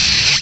Add all new cries
cry_not_pawniard.aif